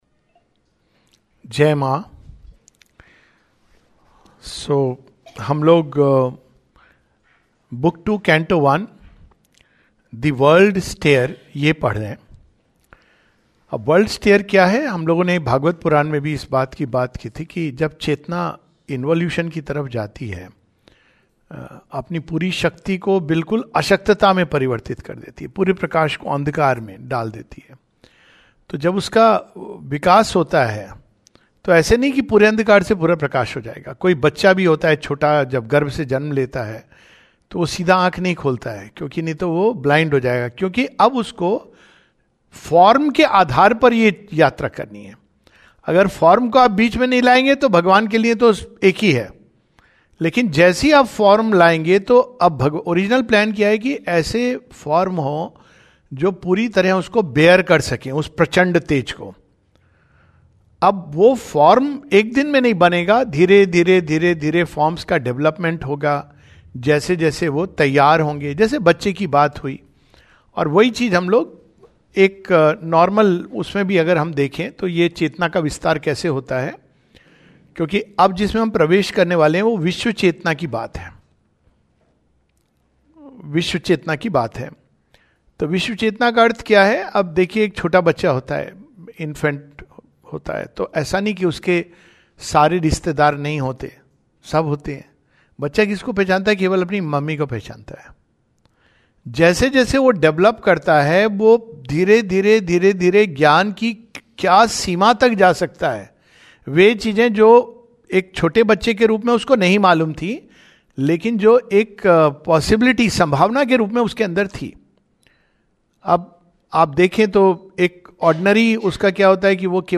Savitri Study class
The passages from Book Two Canto 1 (pages 95-96) were read.